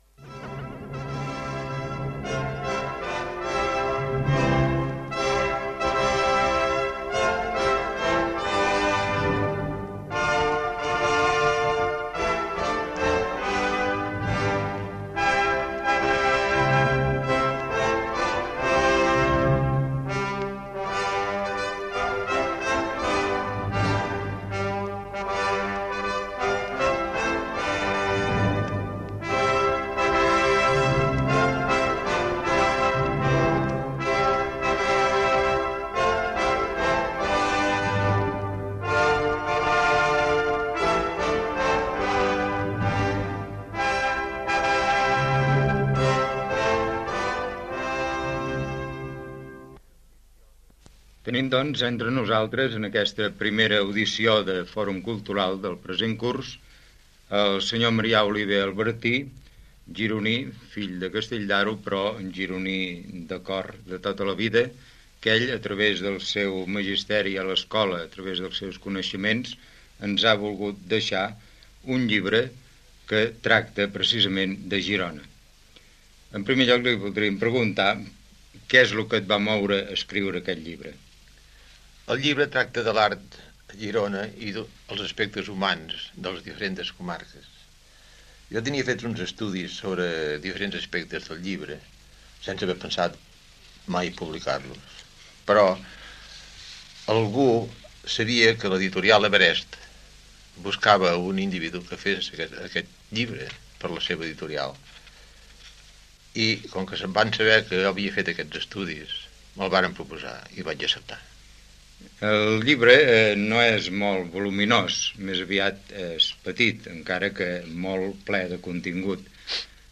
Sintonia i entrevista